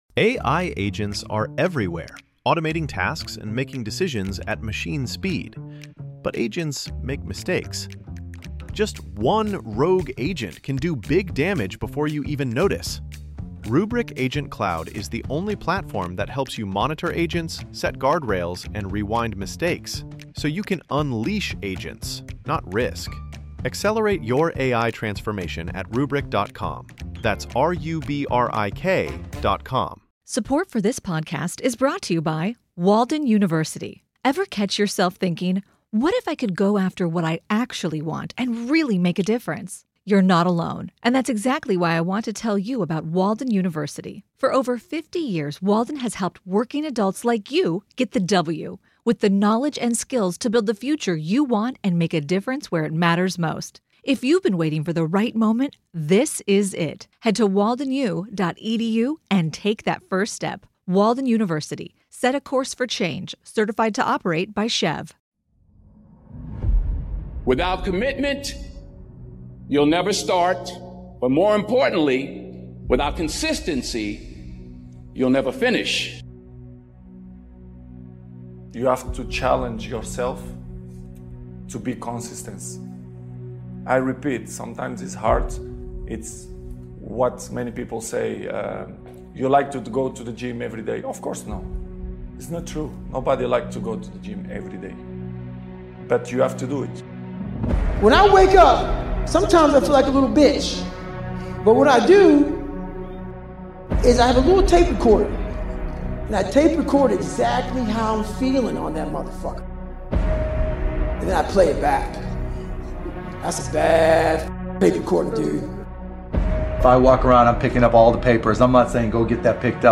Speakers: Tony Robbins Les Brown Eric Thomas Zig Ziglar Mel Robbins Jim Rohn Lisa Nichols BrianTracy Nick Vujicic